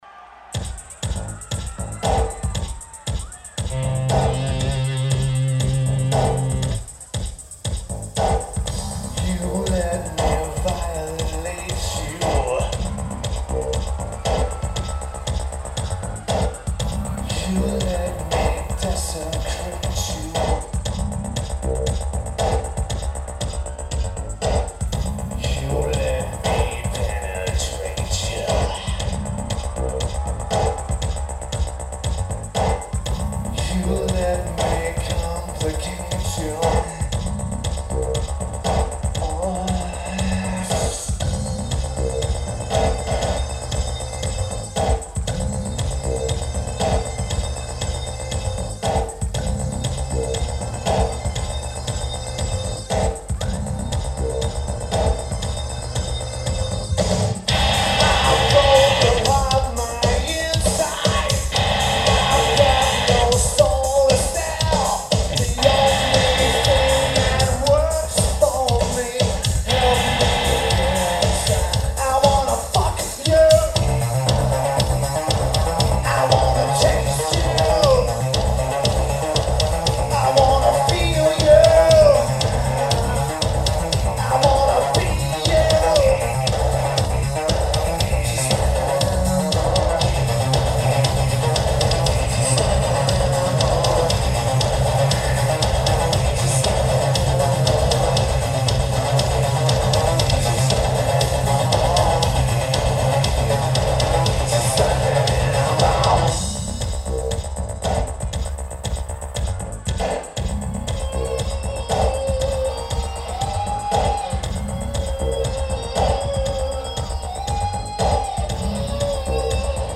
Hershey Park Stadium
Lineage: Audio - AUD (AT831b's + Sony TCD-D7)
Again, the recording is very good.
I'd have to say that it cleaned up really well.